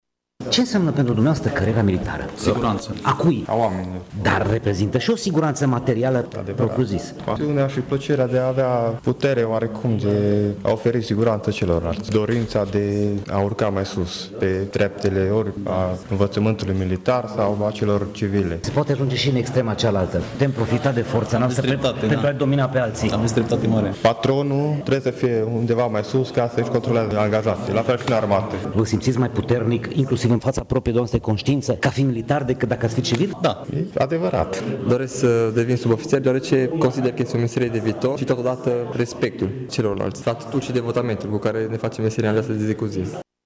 Alți tineri civili își doresc o carieră militară, fiind convinși că este alegerea bună: